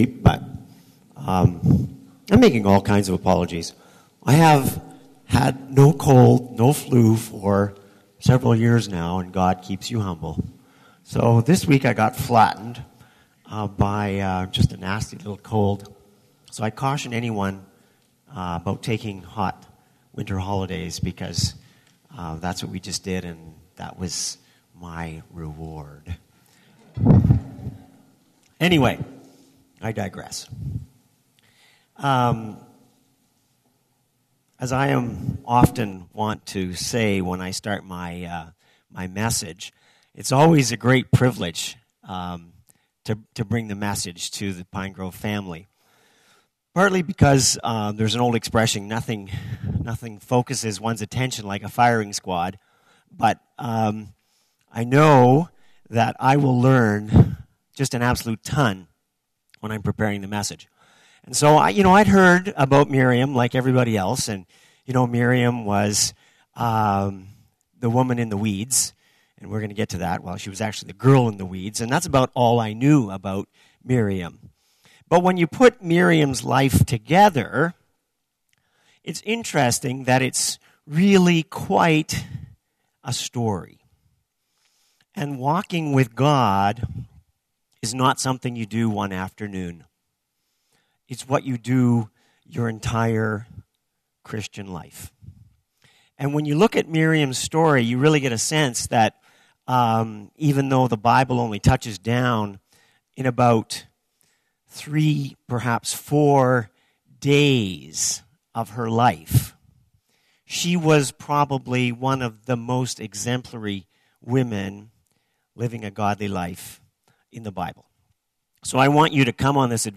This sermon is based on the story of Miriam.